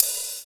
64 OP HAT 2.wav